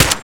q2mg_fire.ogg